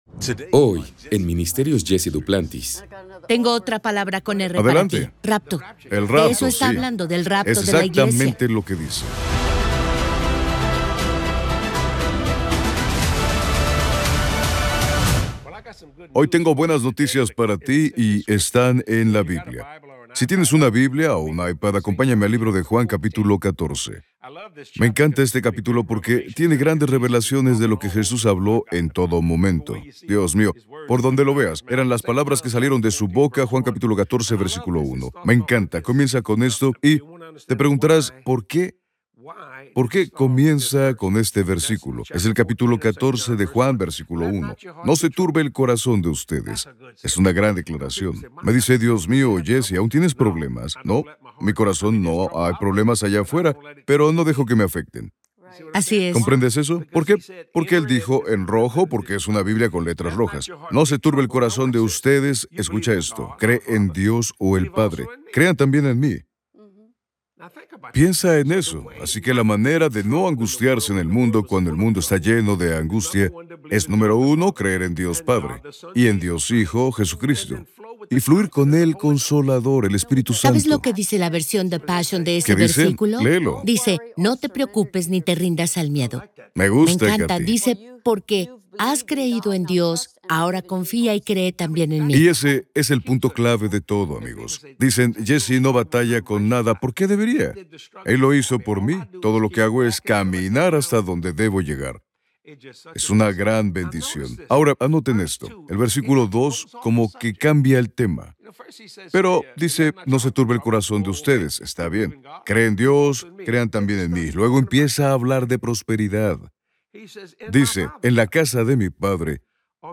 En esta emocionante charla en la SALA DE JUNTAS